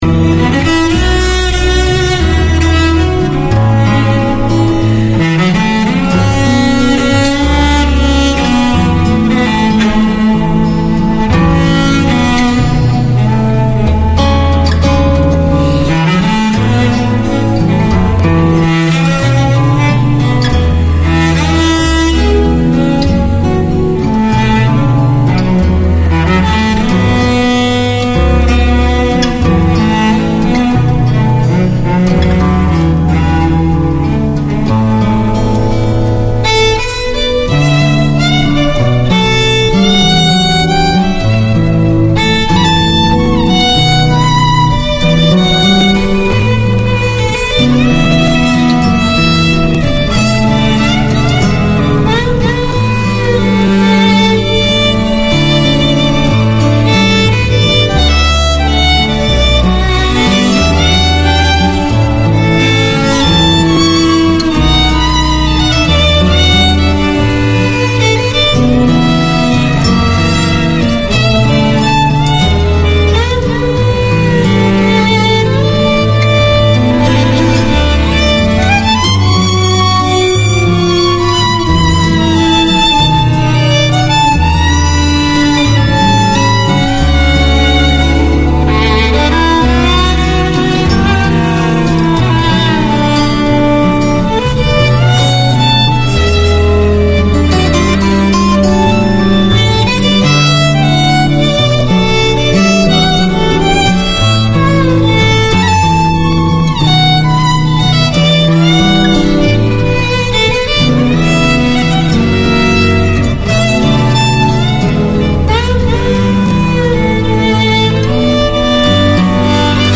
Konkani is the language of Goa, Mangalore, Karwar from South of Maharshtra to north of Kerala and is only spoken, rather than written, so radio is the perfect place to keep the language alive in the Kiwi Konkani community. Your hosts, the Good Guys, play a rich selection of old and contemporary Konkani music, talk with local community personalities, present short radio plays, connect with community events and promote the culture and traditions.